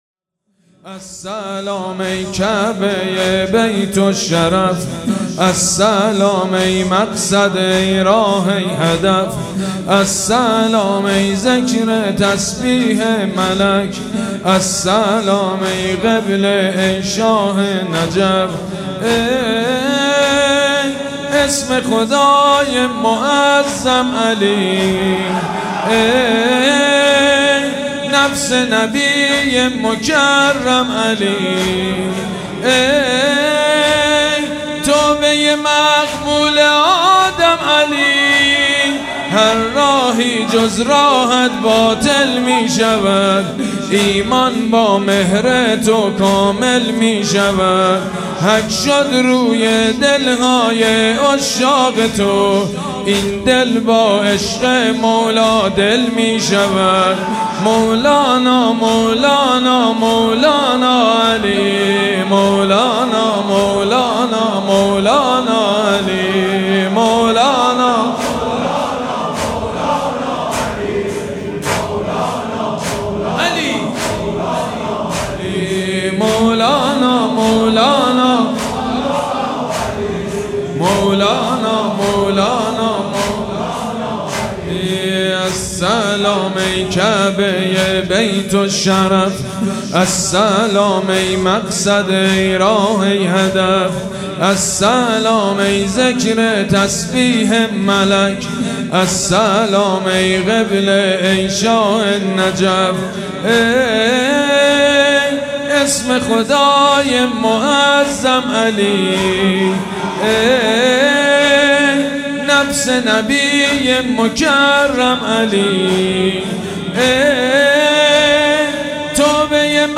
مراسم مناجات شب نوزدهم ماه رمضان
حسینیه ریحانة‌الحسین(س)
مداح
حاج سید مجید بنی فاطمه